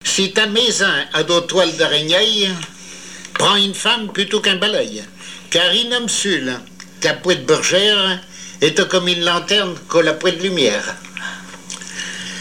Patois local
Genre dicton
émission La fin de la Rabinaïe sur Alouette